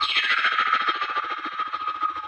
Index of /musicradar/rhythmic-inspiration-samples/105bpm